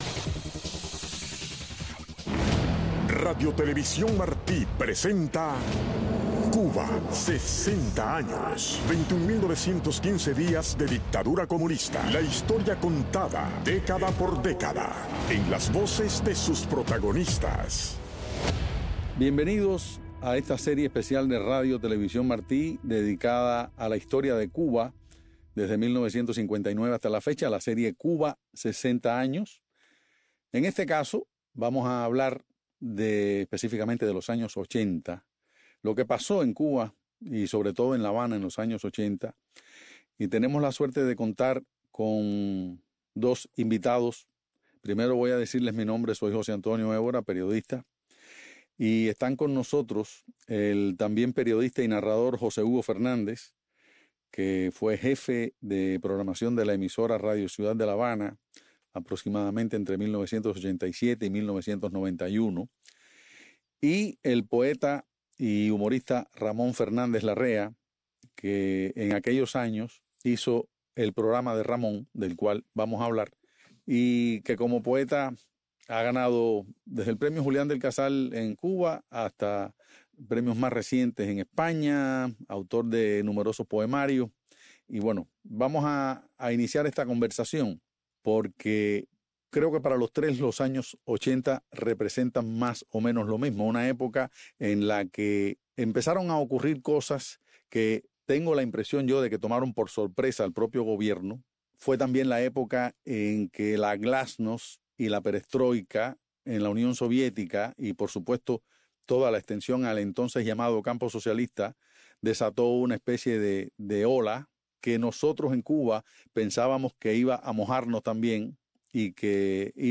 Invitados